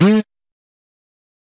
interupted.ogg